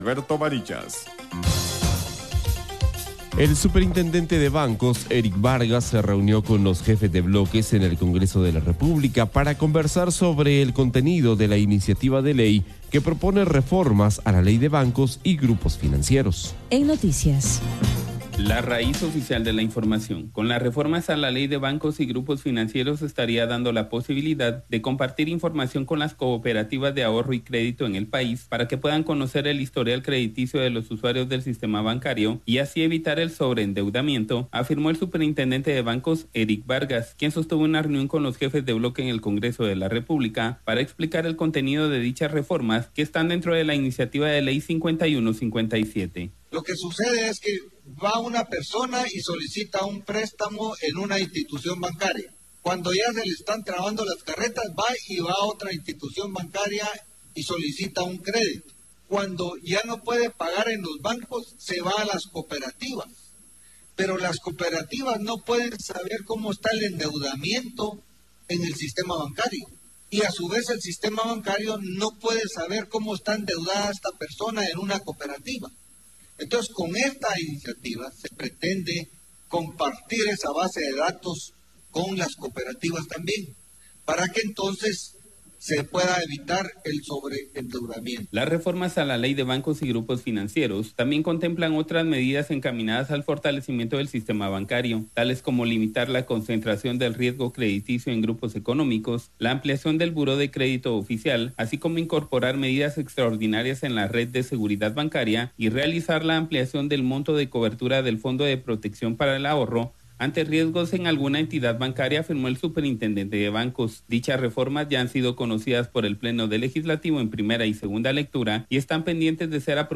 Noticias Iniciativa de Ley 5157